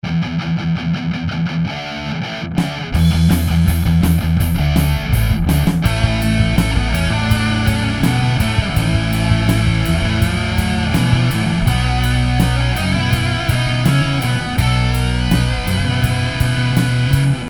Âîò ìèêñèê çàïèñàë. Áàñ òîæå ÷åðåç ýòîò ïðåä :)